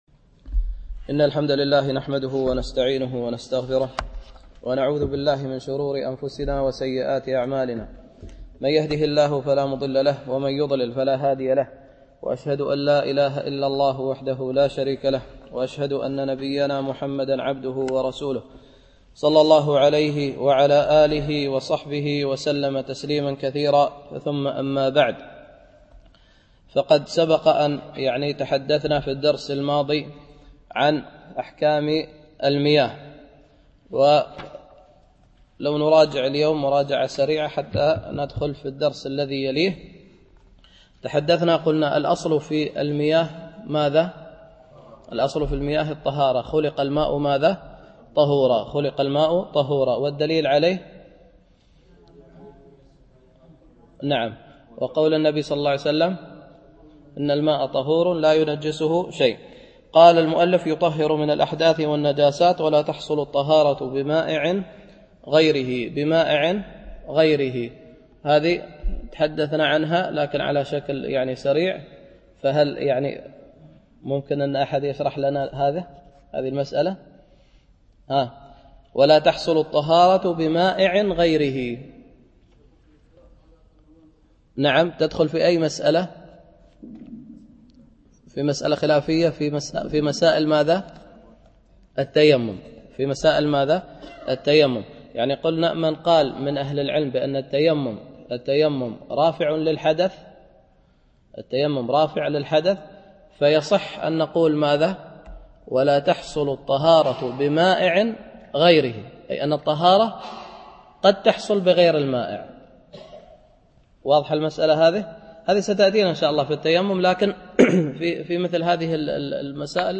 شرح عمدة الفقه ـ الدرس الثاني
Mono